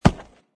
woodgrass.mp3